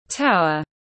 Tower /taʊər/